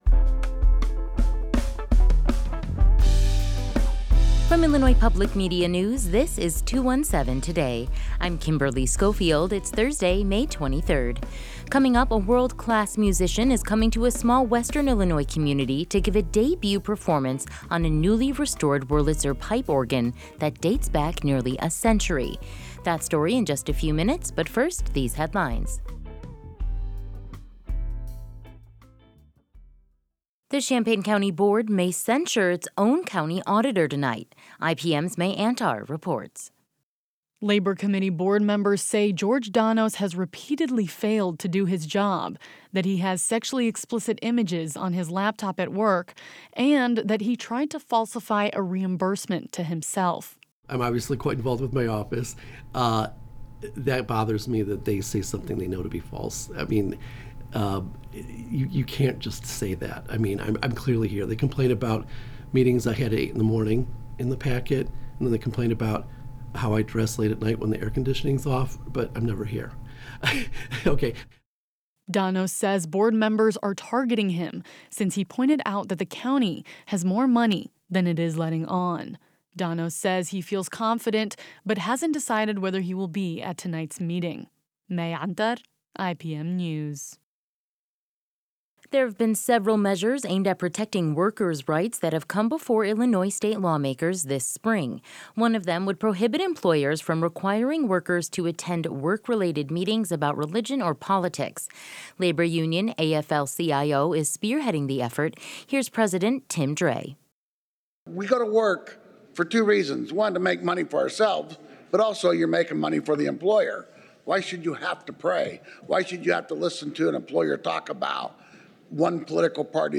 Today's headlines: